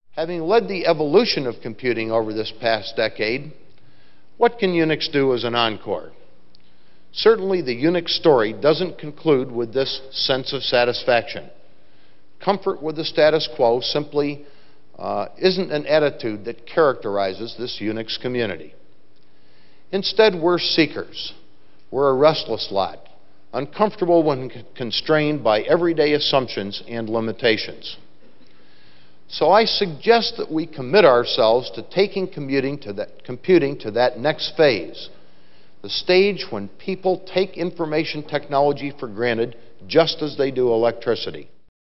HP: Lew Platt Keynote Address from UniForum '96